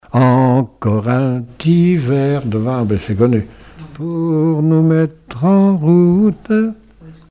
circonstance : bachique
regroupement de chanteurs locaux
Pièce musicale inédite